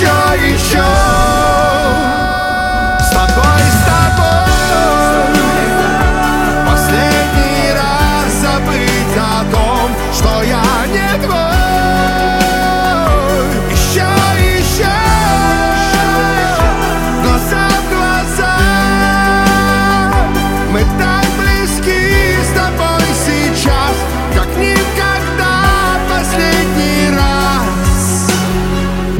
• Качество: 192, Stereo
поп
громкие
эстрадные